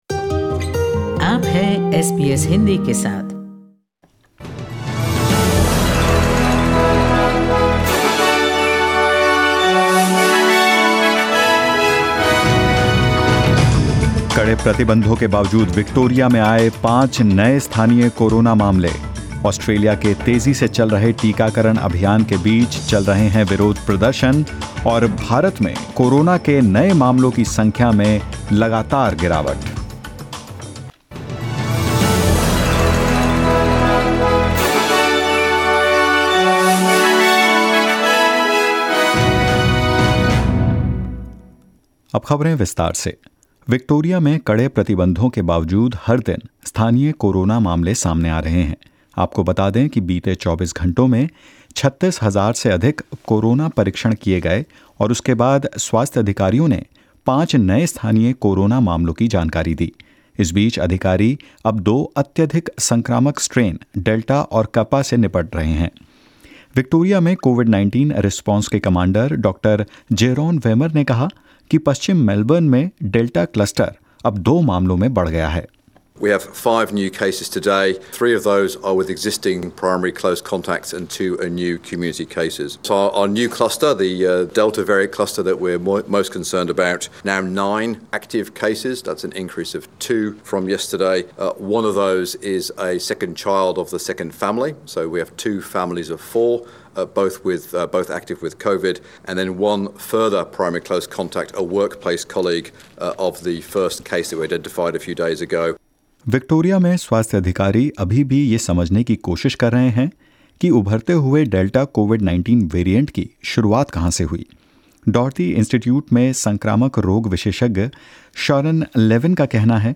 In this latest SBS Hindi News bulletin of Australia and India: Victorian health authorities continue to investigate Melbourne's outbreak as five new community cases are recorded; Russia seeks W-H-O approval for its COVID-19 vaccine and more.